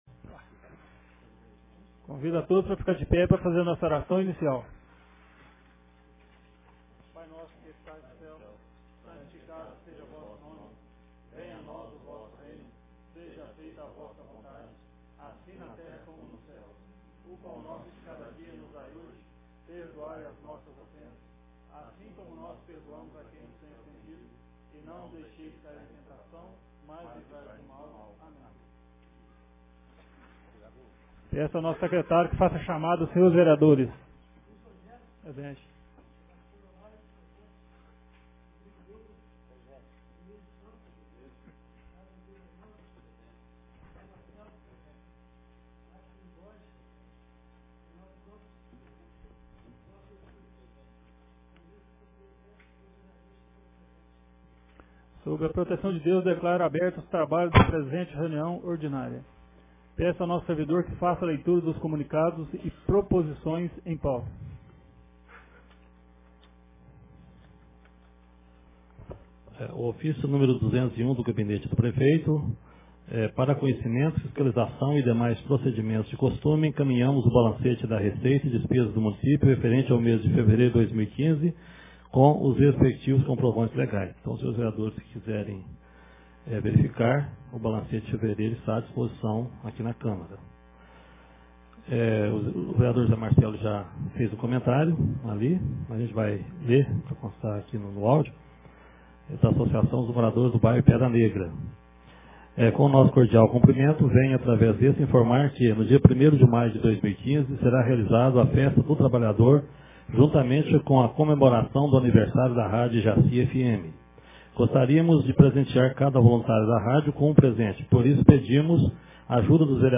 Ata da 10ª Reunião Ordinária de 2015